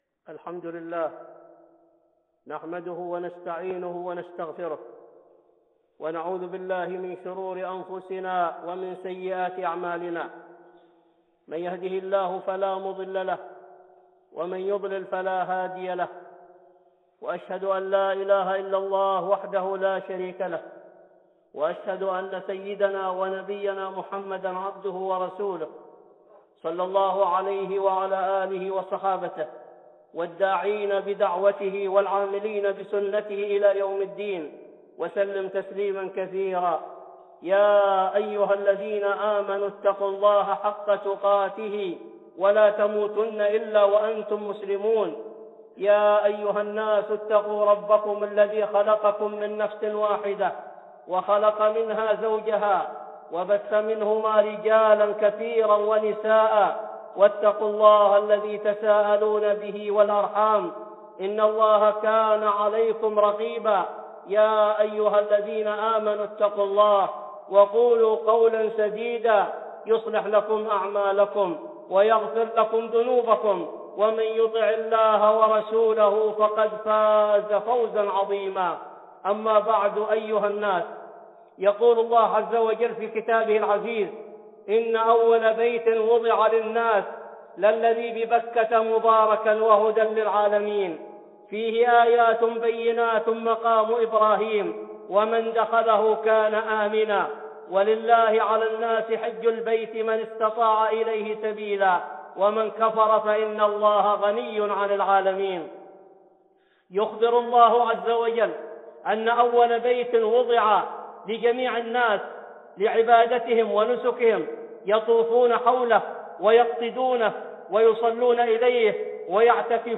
(خطبة جمعة) بعنوان